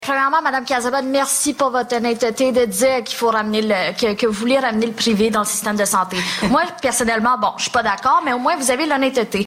Un débat mettant en vedette les candidats de 8 partis était présenté sur les ondes du FM 103,3, ce mercredi.